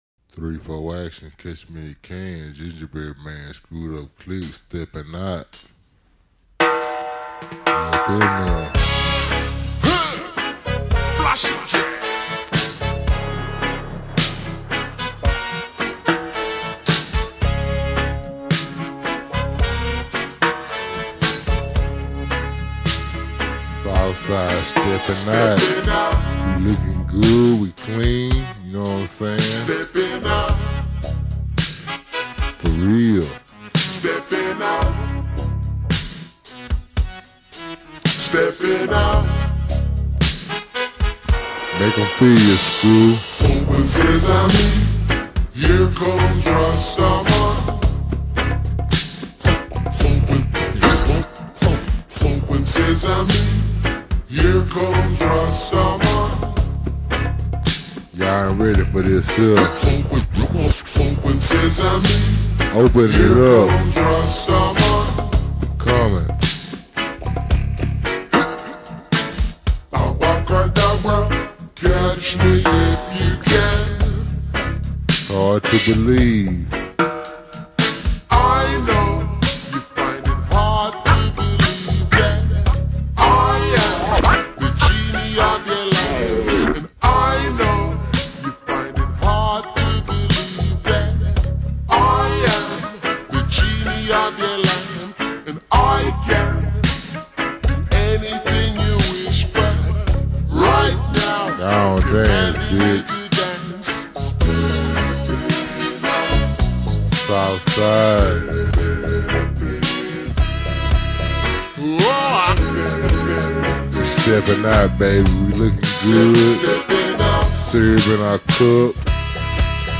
you can hear the screwed & chopped version below